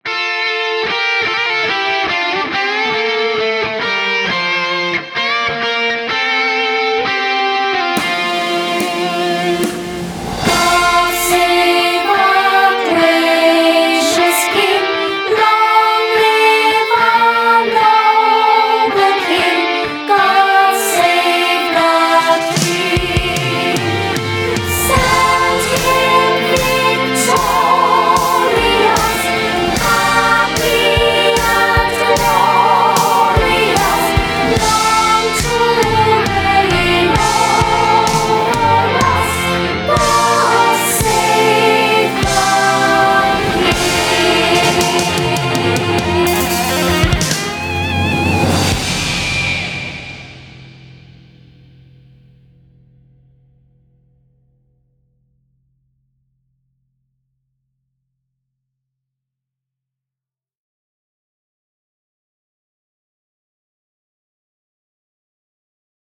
Click HERE to download the MP3 Vocal Track.